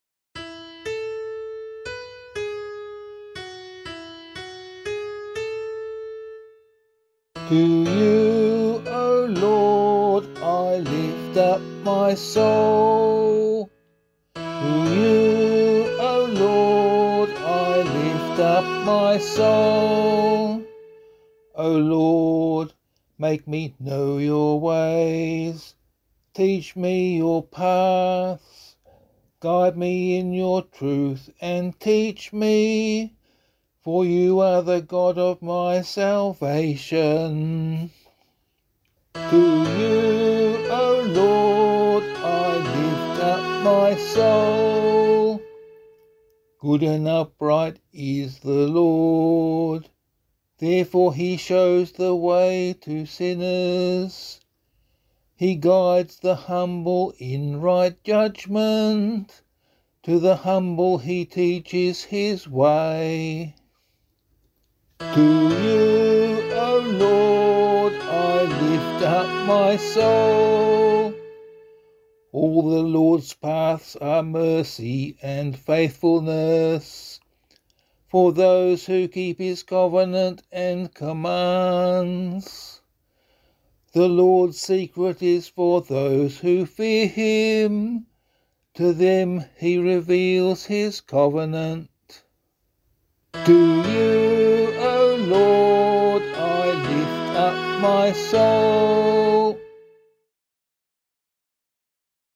001 Advent 1 Psalm C [APC - LiturgyShare + Meinrad 8] - vocal.mp3